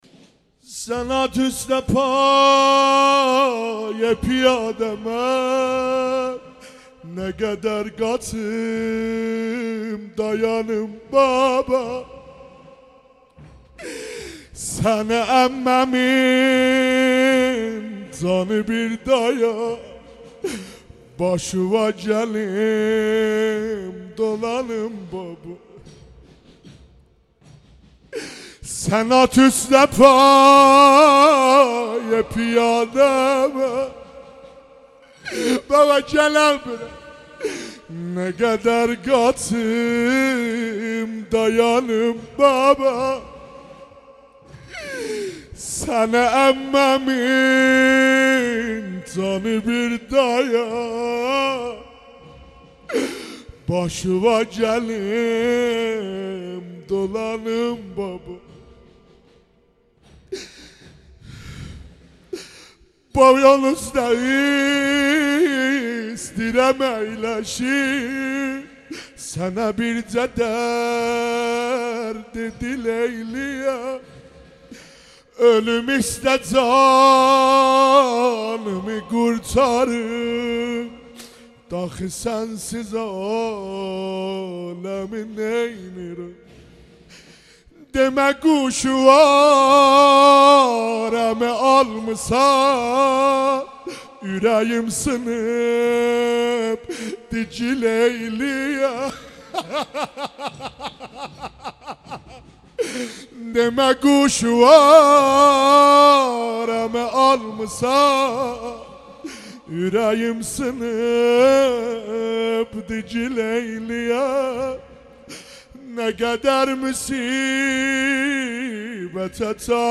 مداحی آذری نوحه ترکی